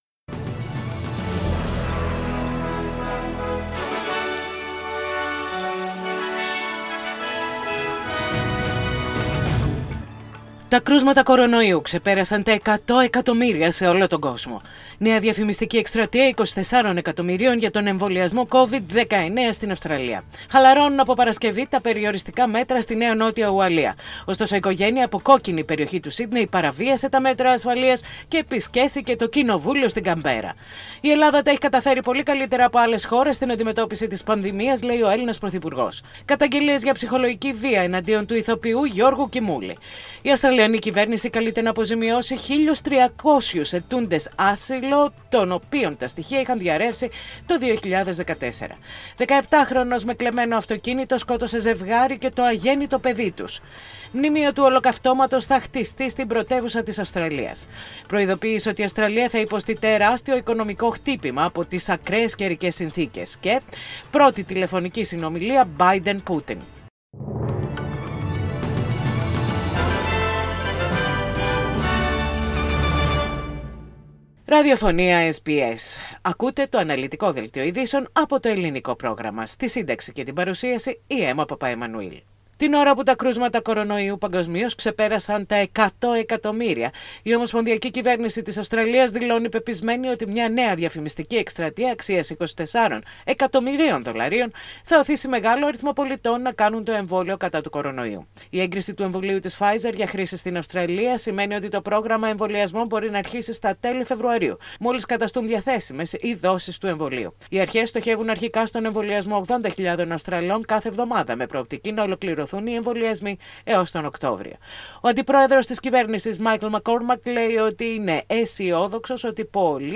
Δελτίο ειδήσεων - Τετάρτη 27.1.21
Οι κυριότερες ειδήσεις της ημέρας από το Ελληνικό πρόγραμμα της ραδιοφωνίας SBS.